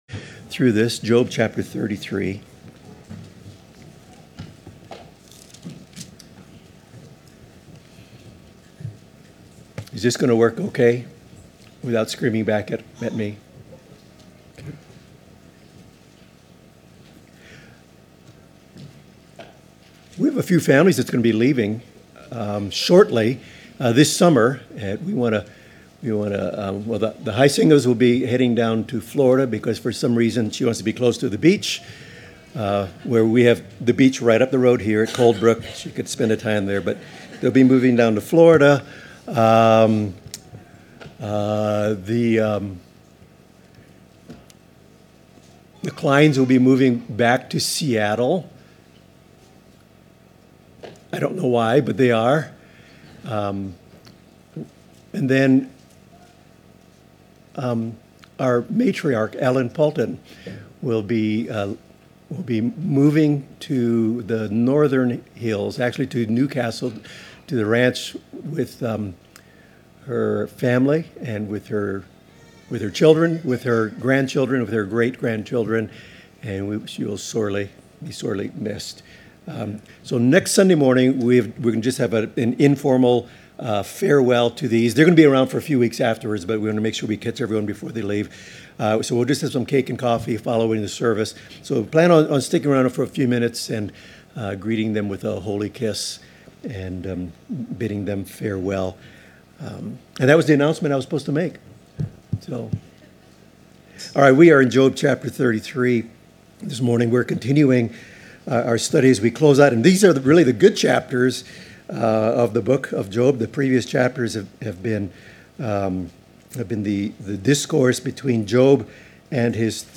Job 33 Service Type: Morning Service Topics